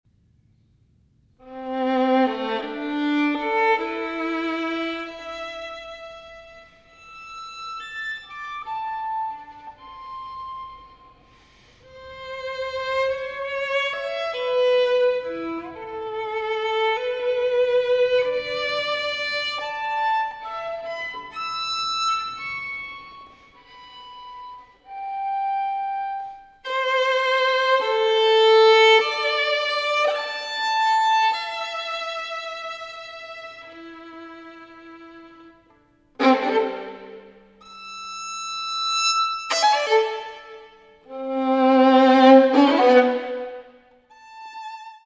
for Violin